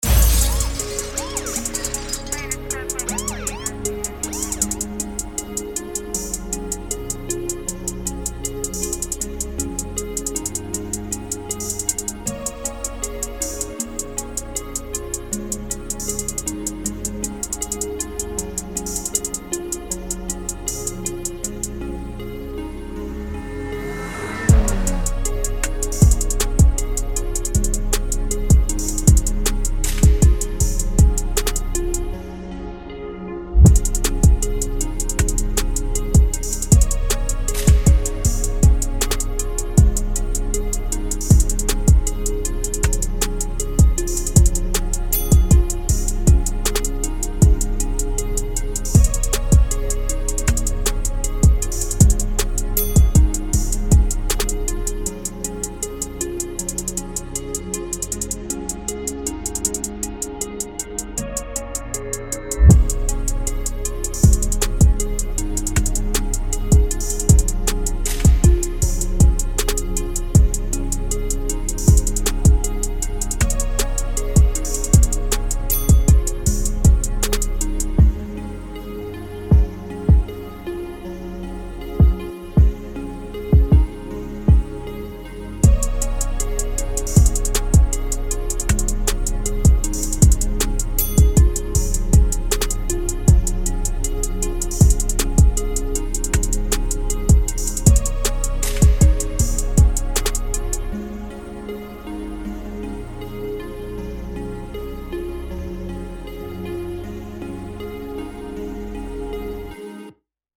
Жанр: Trap
Rap Спокойный 157 BPM